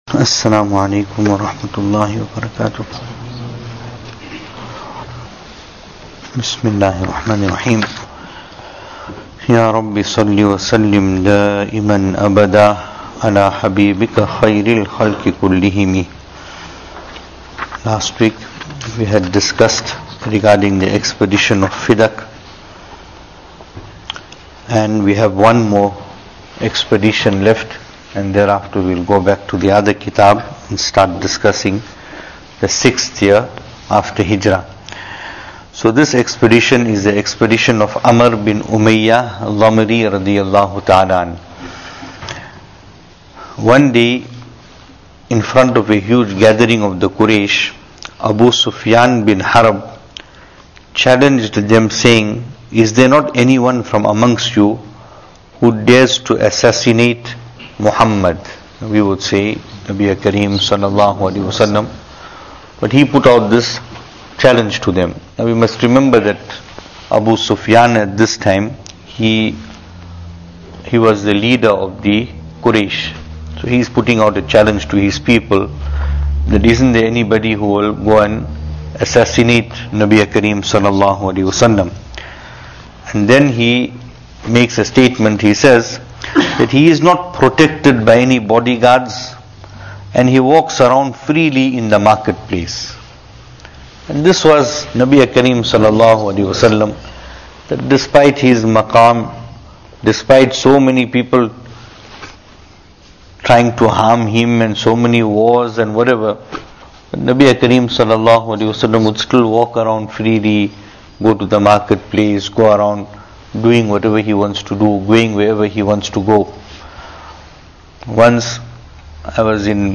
Venue: Masjid Taqwa, Pietermaritzburg | Series: Seerah Of Nabi (S.A.W)
Service Type: Majlis